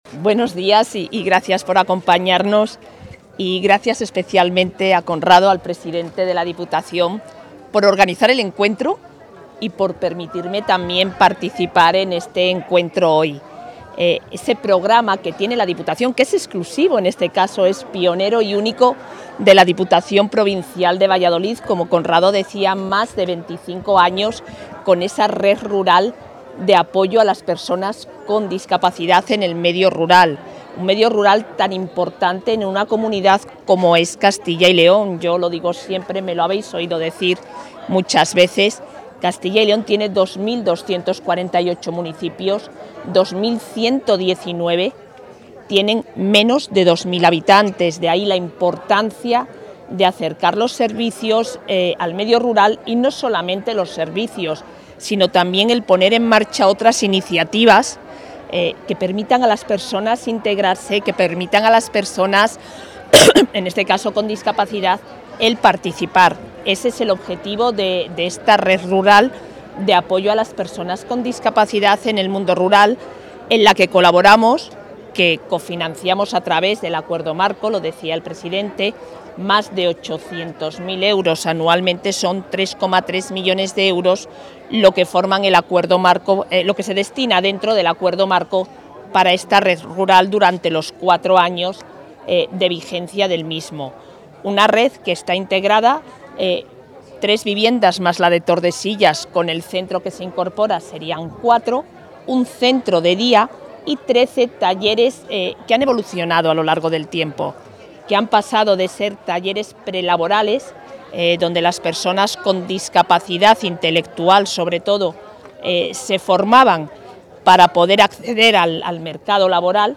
Declaraciones de la vicepresidenta de la Junta.